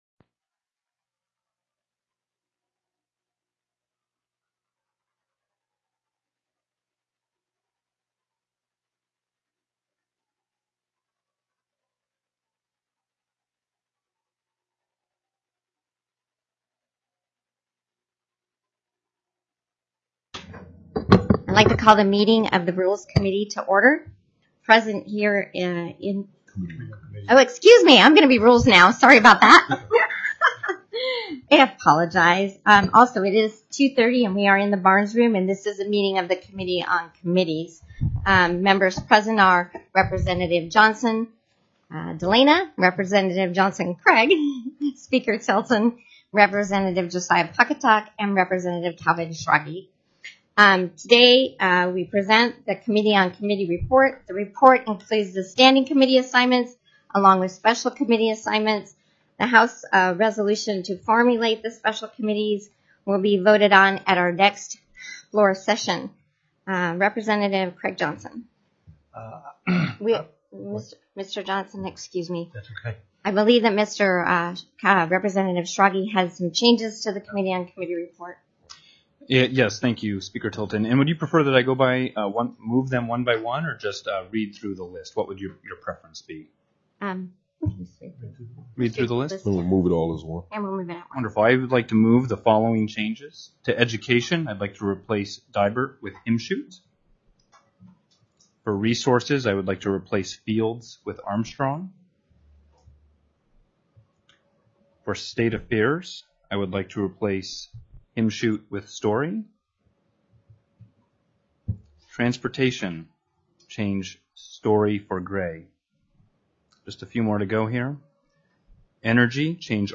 + House Committee Appointments TELECONFERENCED
Representative Cathy Tilton, Chair